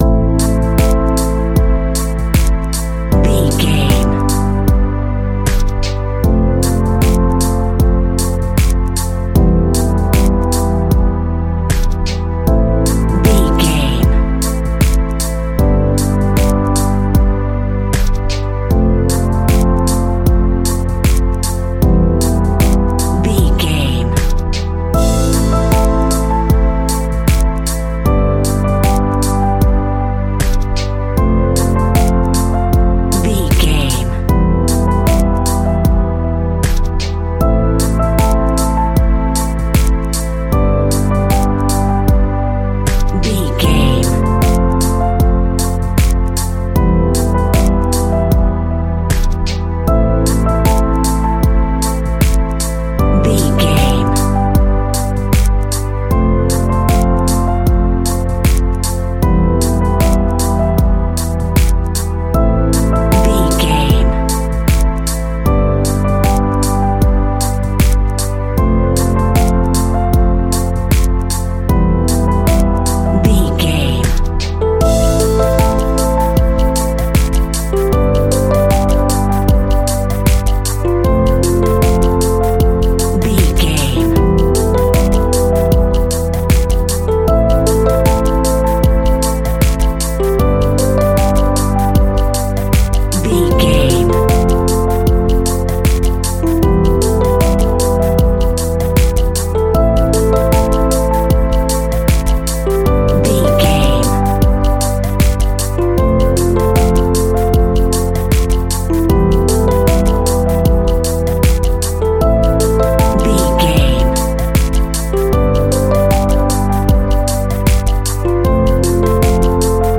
Slow Beat to Rap to.
Aeolian/Minor
hip hop
laid back
hip hop drums
hip hop synths
piano
hip hop pads